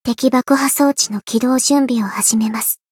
灵魂潮汐-阿卡赛特-互动-厌恶的反馈.ogg